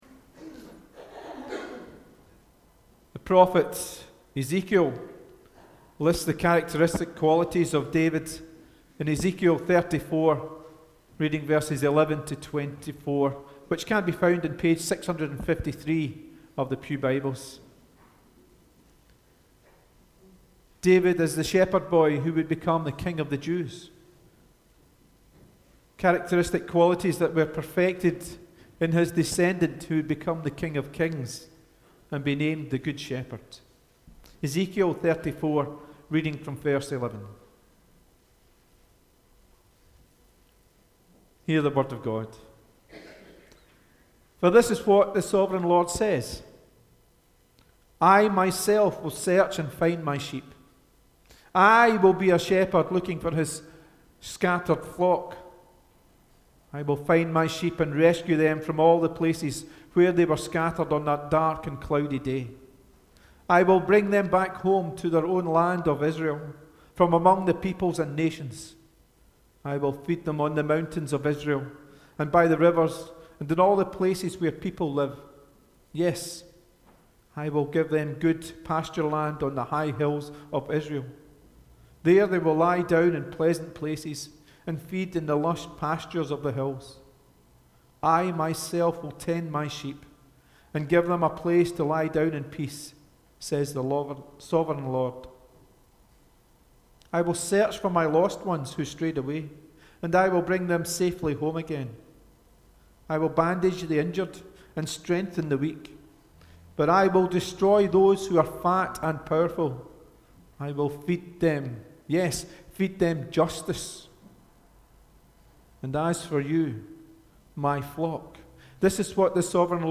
The Scripture Readings prior to the Sermon are Ezekiel 34: 11-24 and Matthew 25: 31-46